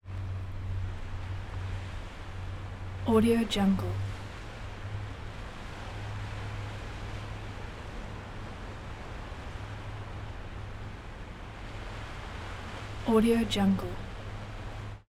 دانلود افکت صدای برخورد آرام موج به صخره های ساحل
Sample rate 16-Bit Stereo, 44.1 kHz
Looped No